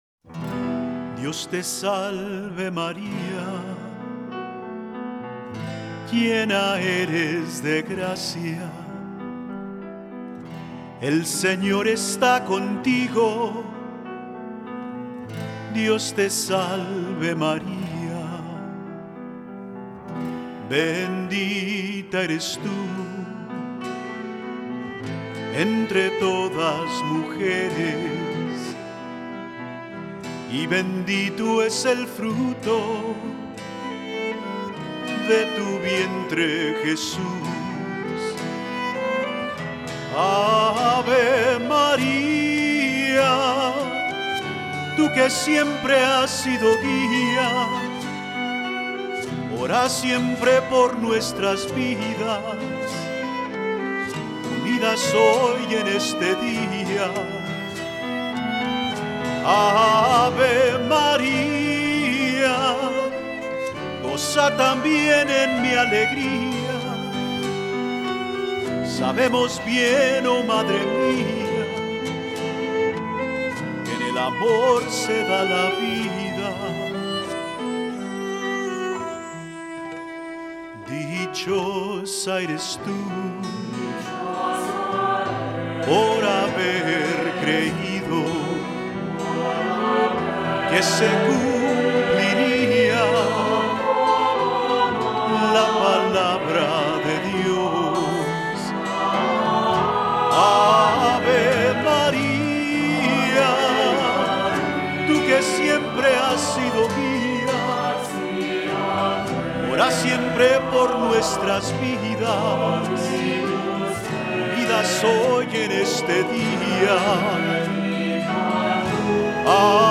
Voicing: Soloist or Soloists,2-part Choir